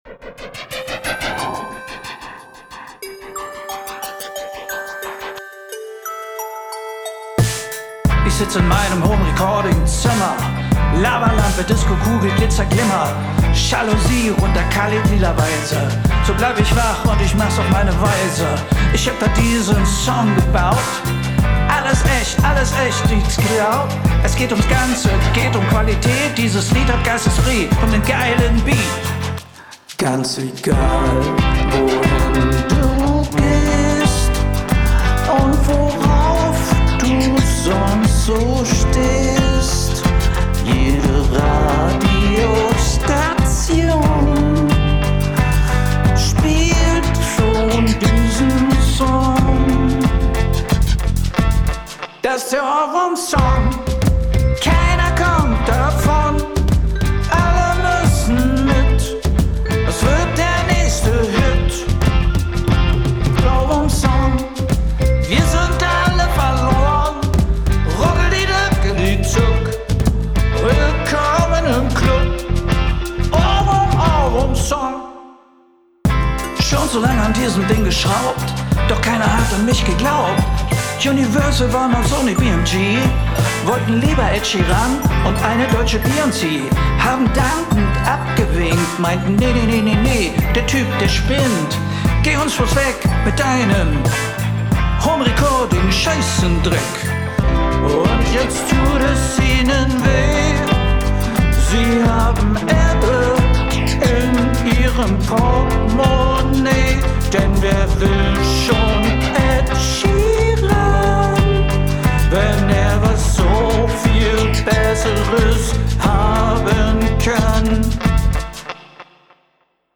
Der I - V - VI - IV - Mainstream - Popsong - Contest
Habe zum ersten Mal Rap probiert und ja, zweite Strophe ging schon. Erster Prechorus ist nicht optimal gesungen hätte ich sehr gerne nochmal neu gemacht.